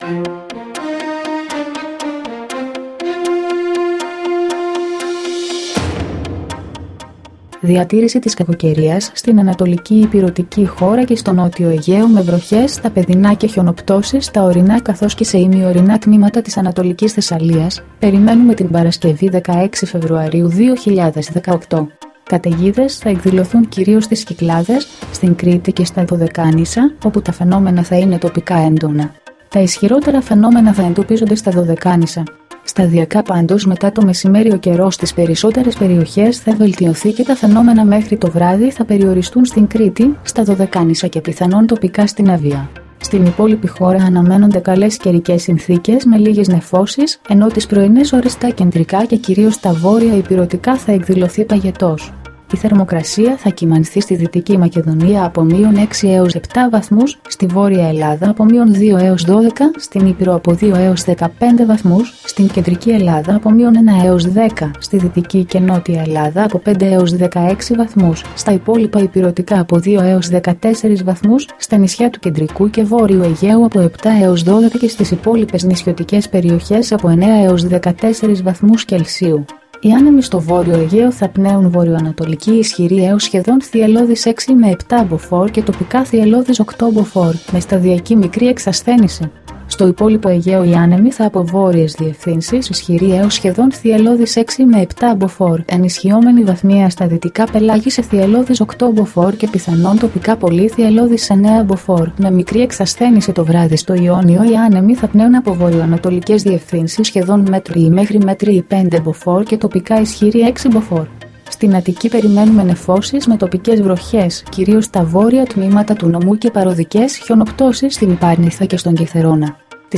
dailyforecastc.mp3